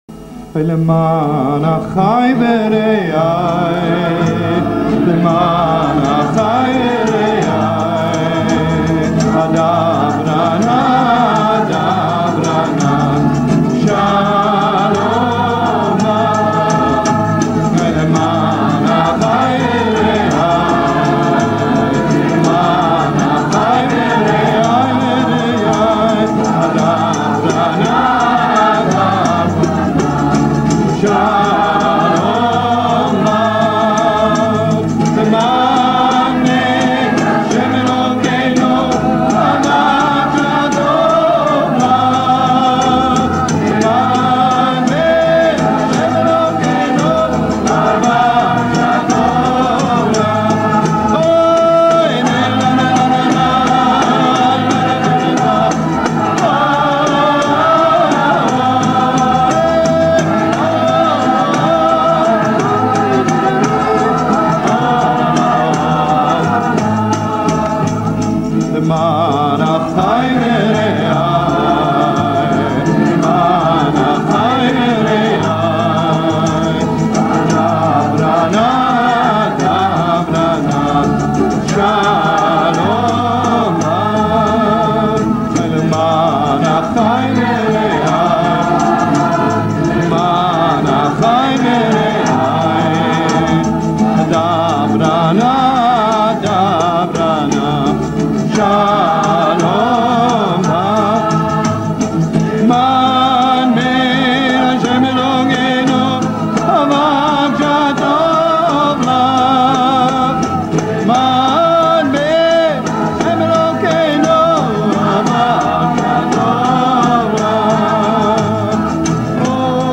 הופעה באולפני הטלוויזיה הישראלית